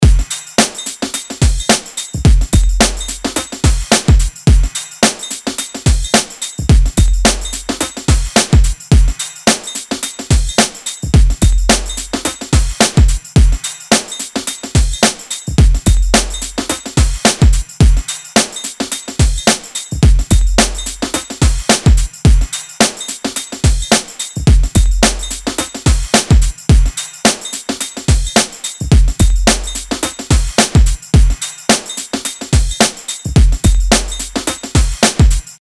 LP 212 – DRUM LOOP – EIGHTIES POP – 108BPM